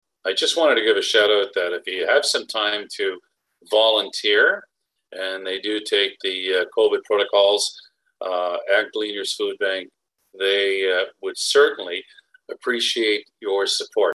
But at Monday’s council meeting Councillor Sean Kelly said because of the COVID-19 pandemic the number of volunteers working at the food bank has been lower.